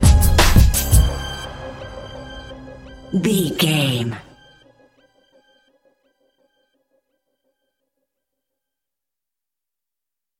Aeolian/Minor
SEAMLESS LOOPING?
DOES THIS CLIP CONTAINS LYRICS OR HUMAN VOICE?
WHAT’S THE TEMPO OF THE CLIP?
drum machine
synthesiser